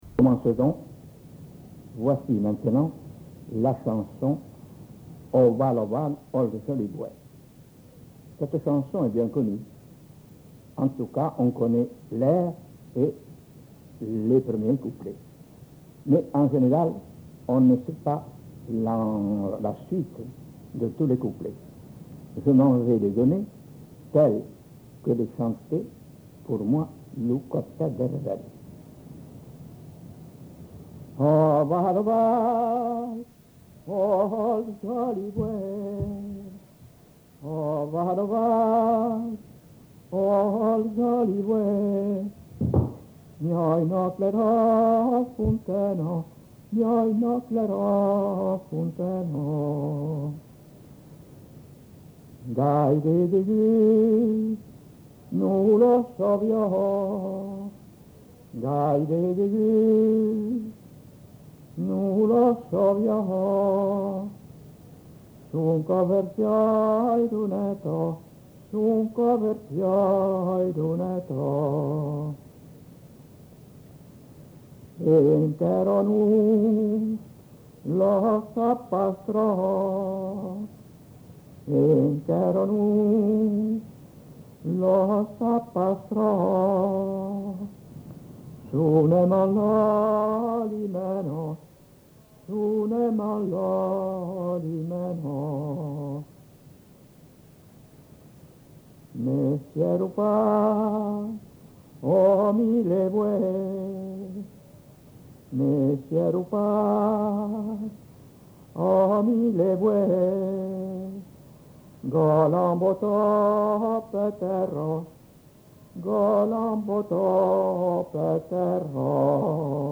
Aire culturelle : Quercy
Genre : chant
Type de voix : voix d'homme Production du son : chanté
[enquêtes sonores]